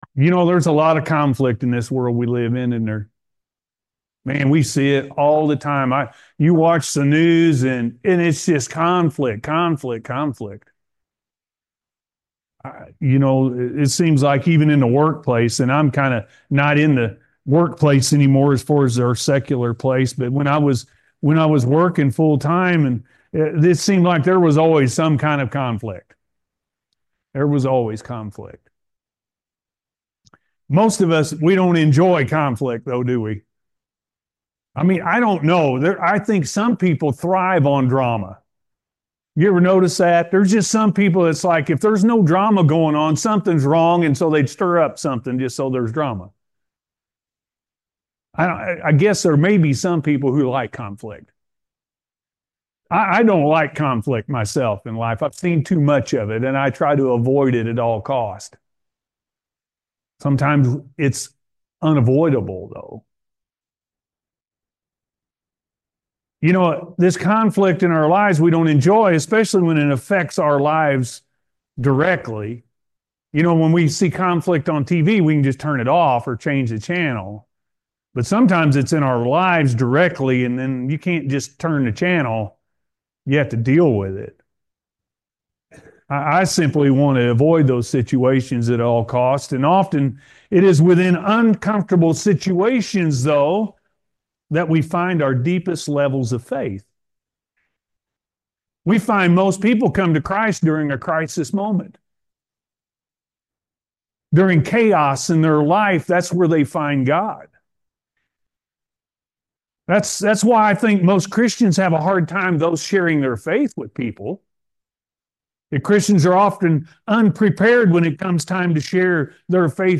The Great Commission-A.M. Service